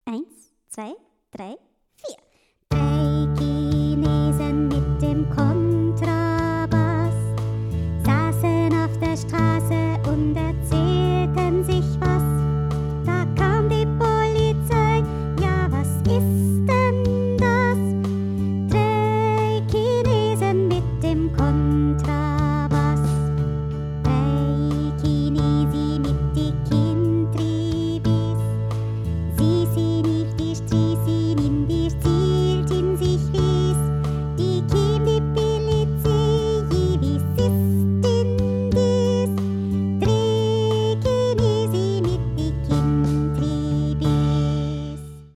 Mit Gesang
3.-in-D-Dur.mp3